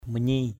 /mə-ɲi:/ manyi mv} [Cam M] (đg.) gây tiếng động, kêu = faire du bruit = to make a noise, make sound. ciim manyi c`[ mv} chim hót = l’oiseau chante =...